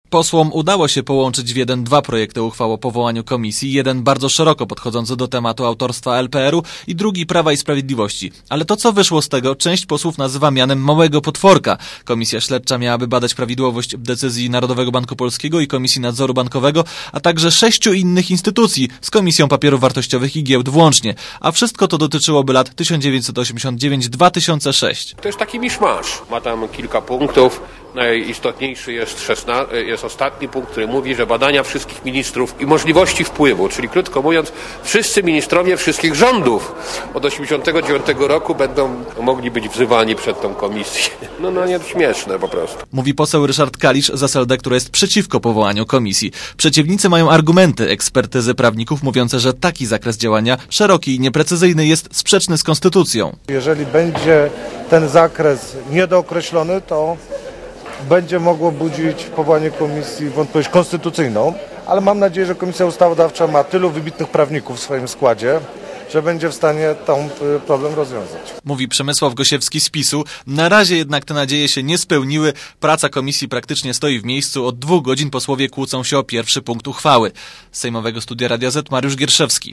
reportera Radia ZET